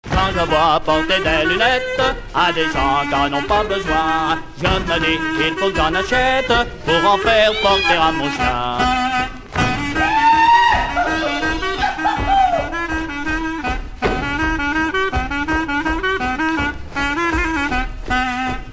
Mazurka
Chants brefs - A danser
danse : mazurka